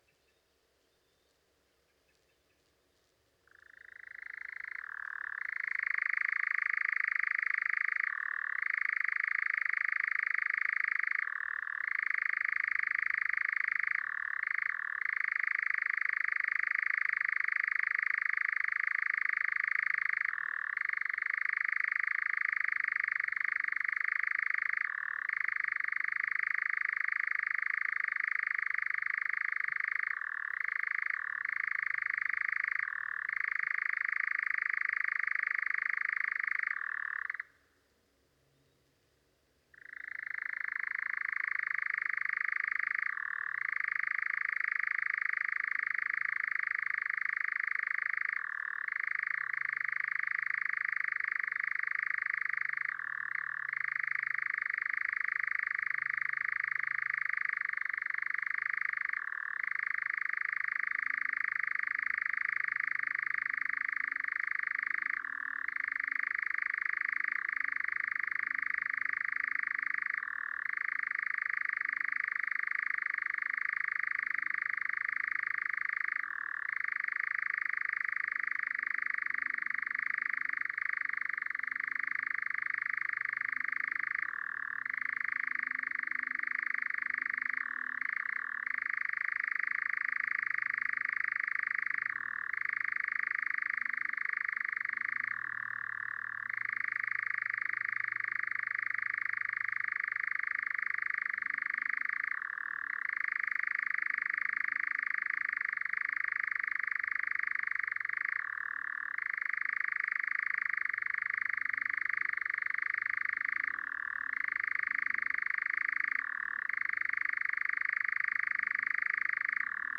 XC731874-noitibó-cinzento-Caprimulgus-europaeus